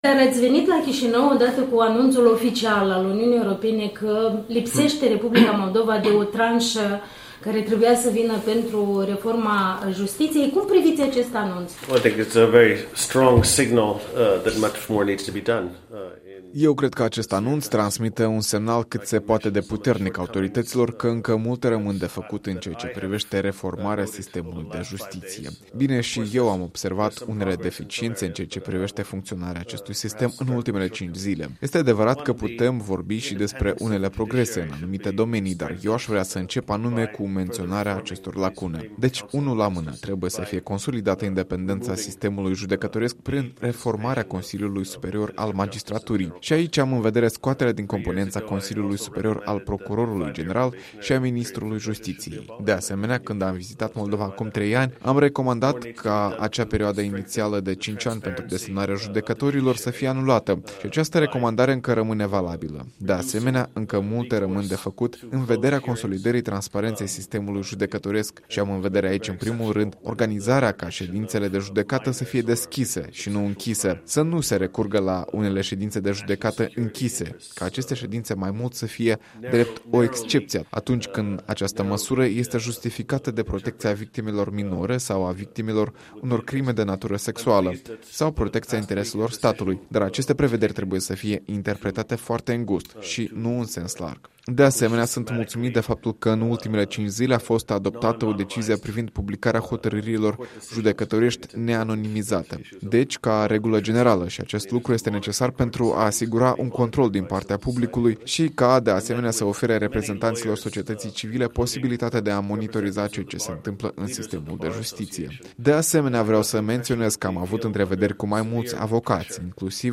Un interviu la Chișinău cu comisarul Consiliului Europei pentru drepturile omului.
Interviu cu comisarul Consiliului Europei pentru drepturile omului, Nils Muiznieks